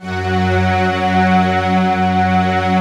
SI1 CHIME07R.wav